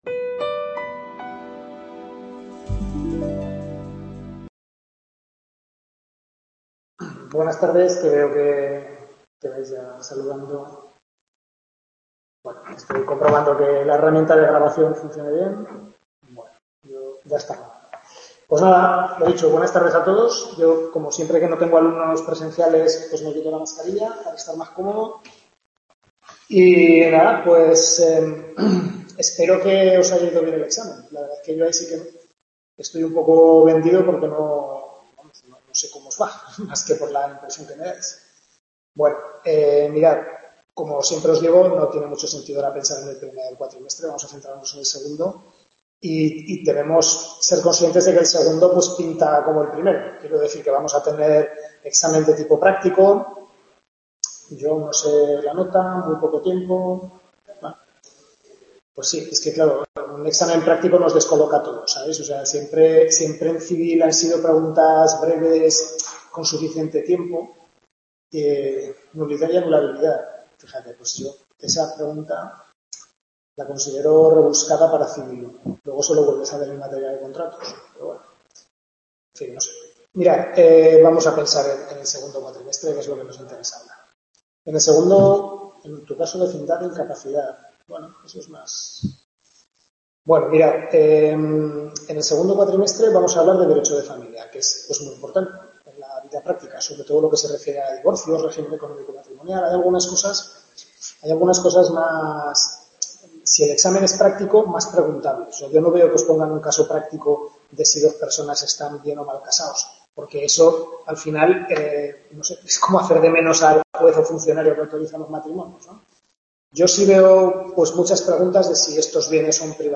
Primera tutoría del segundo cuatrimestre de Civil I (Derecho de Familia), capítulos 1-4 del Manual del profesor LAsarte